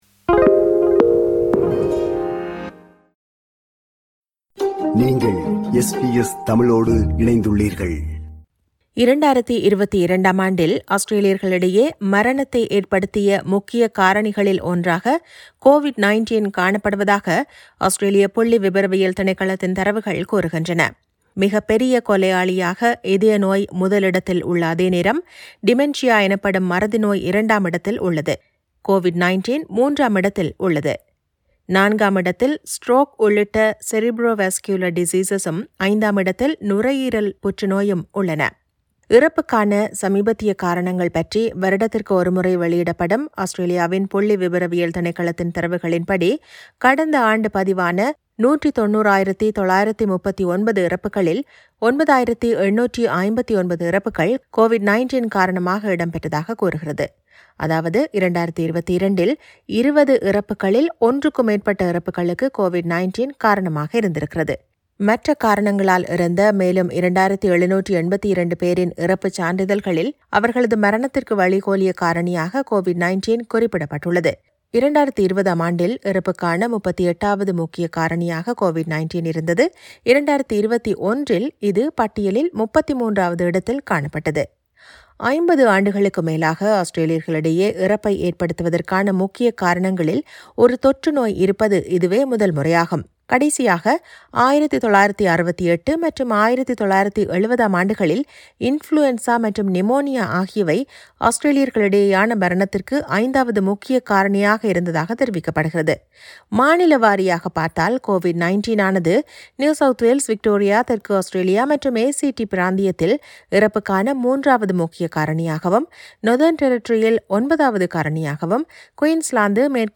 செய்தி விவரணத்தை